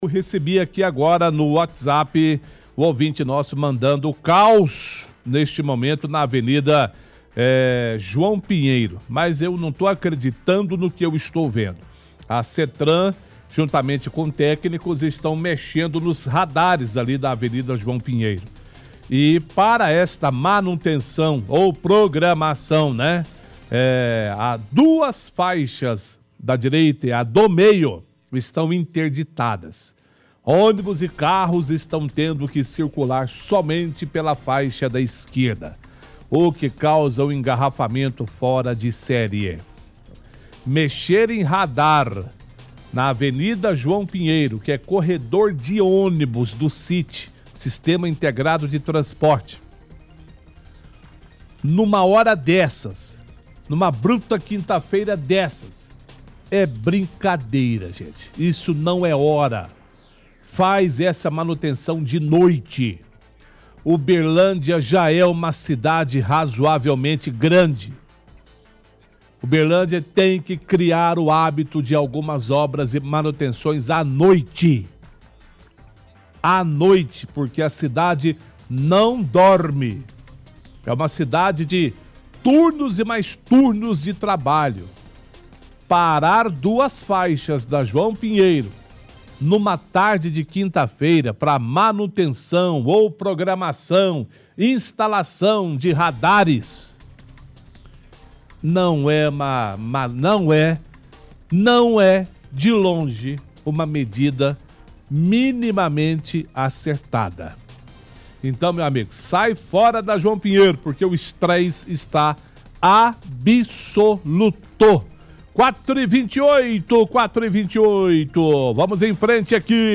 Apresentador fala que Settran e técnicos estão mexendo nos radares da avenida e duas faixas estão interditadas, causando um engarrafamento fora de série. Faz críticas, fala pra fazer essa manutenção à noite, Uberlândia já é uma cidade grande, uma cidade que não dorme.